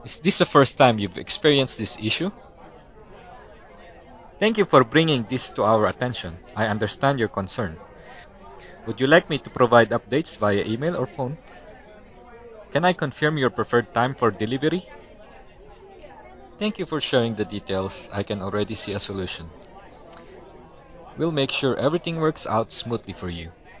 Filipino